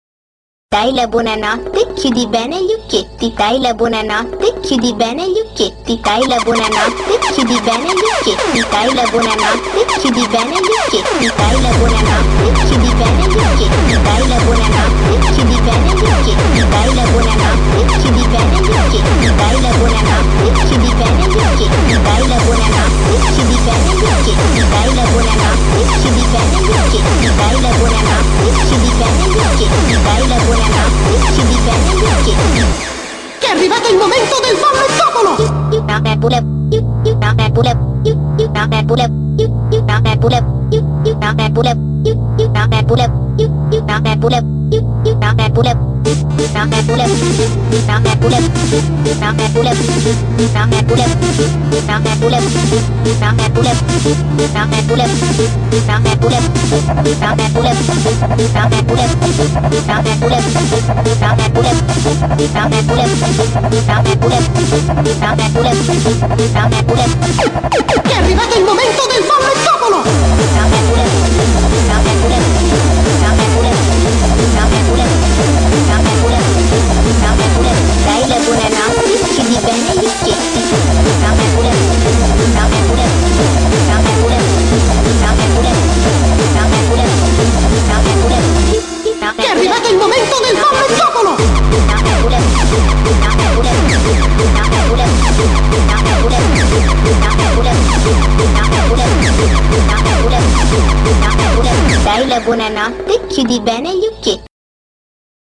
BPM180
Audio QualityCut From Video